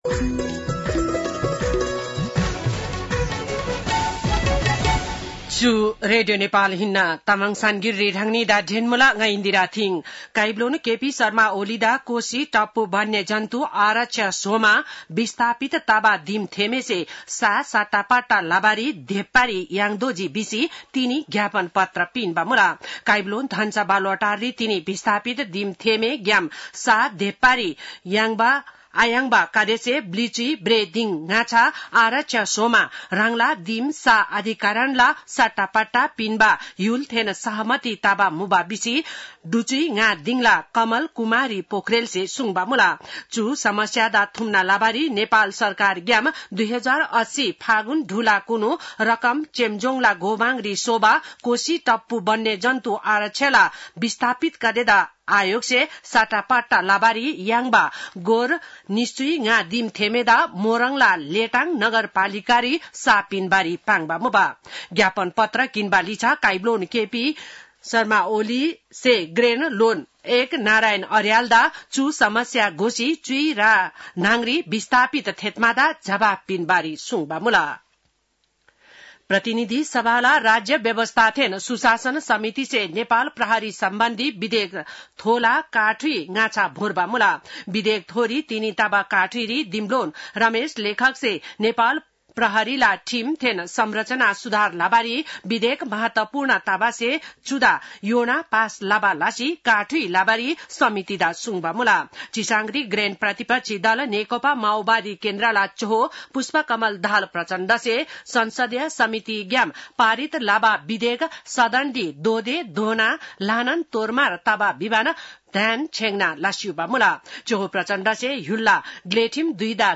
तामाङ भाषाको समाचार : ११ असार , २०८२